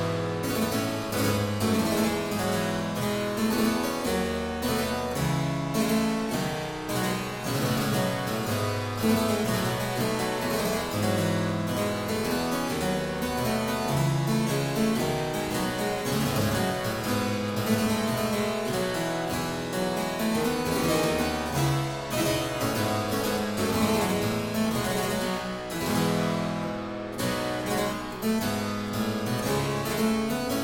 clavecin